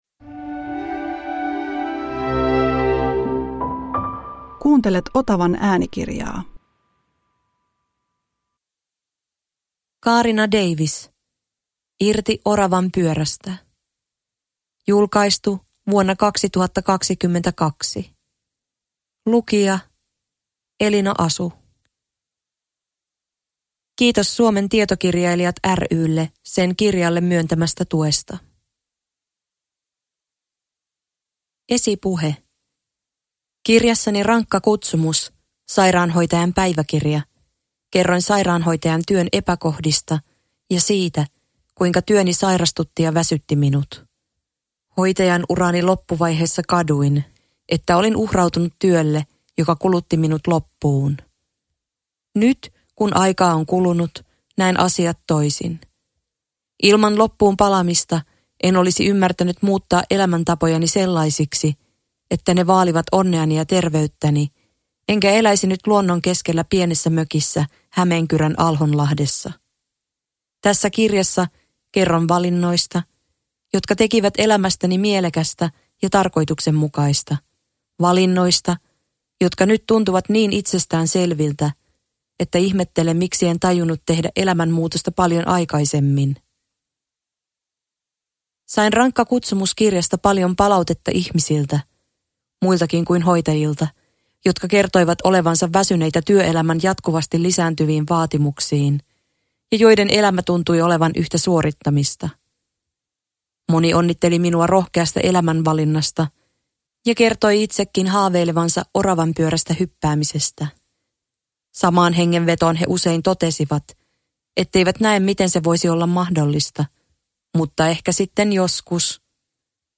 Irti oravanpyörästä – Ljudbok – Laddas ner